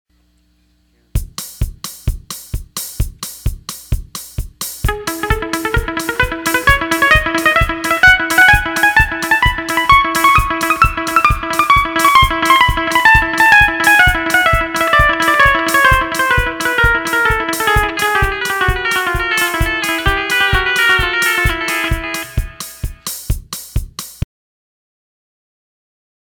The echo delay is set for 346 millisecond delay.
Fast Echo Tab Muted 3.wma